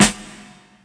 Tuned snare samples Free sound effects and audio clips
• Big Room Steel Snare Drum G# Key 417.wav
Royality free snare drum sound tuned to the G# note. Loudest frequency: 3349Hz
big-room-steel-snare-drum-g-sharp-key-417-u3a.wav